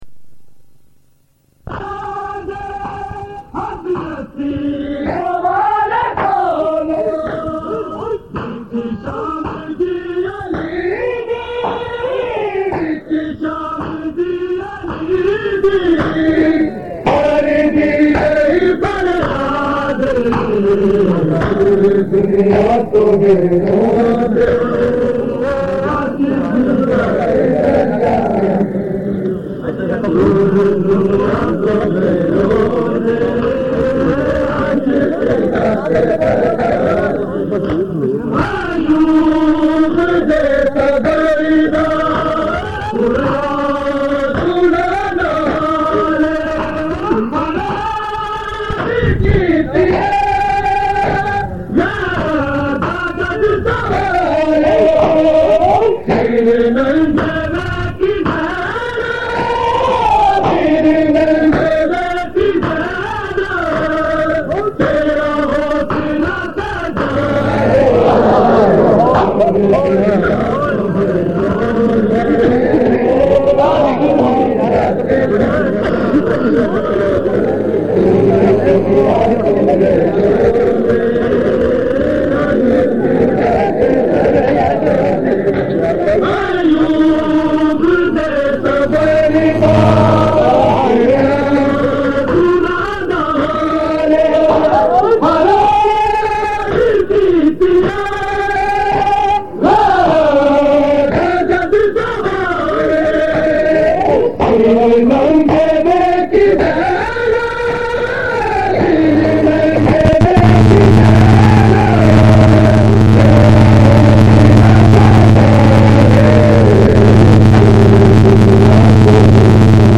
Recording Type: Live
Location: Lahore, Mochi Gaate